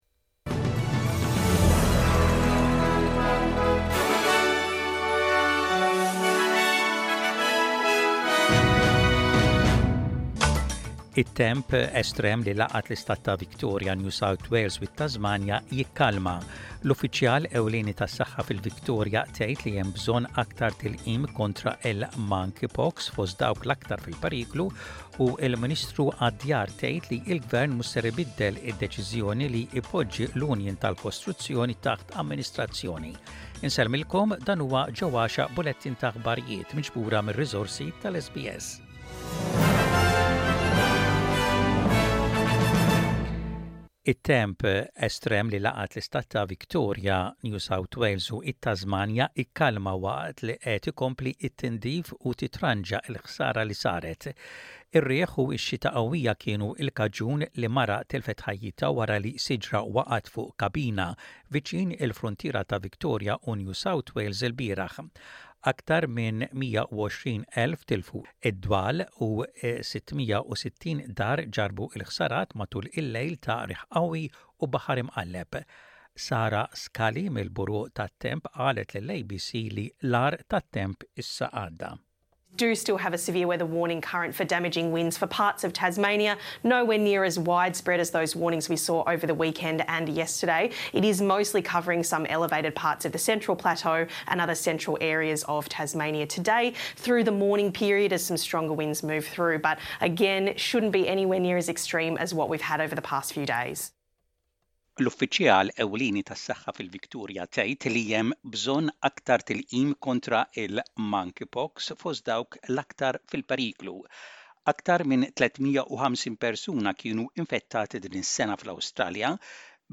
SBS Radio | Aħbarijiet bil-Malti: 03.09.24